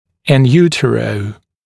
[ɪn ˈjuːtərəu][ин ˈйу:тэроу]внутриутробно